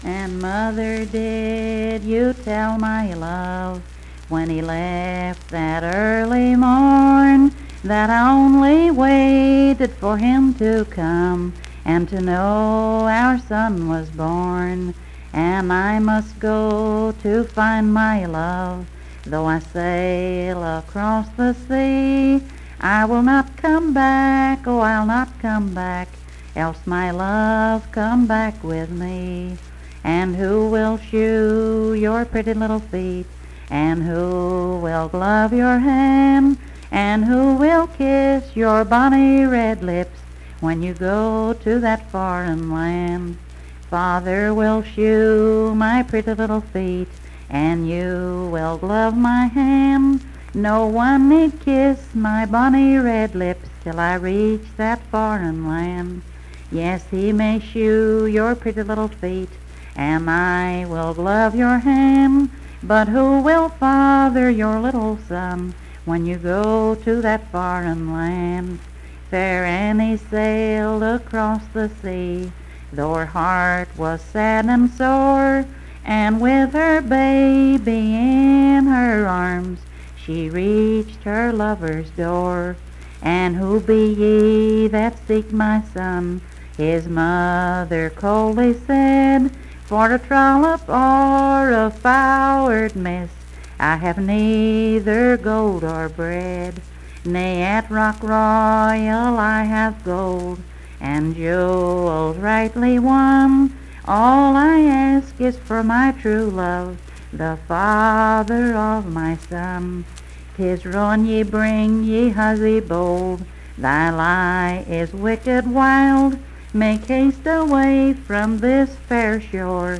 Unaccompanied vocal music performance
Verse-refrain 20(4).
Voice (sung)
Marion County (W. Va.)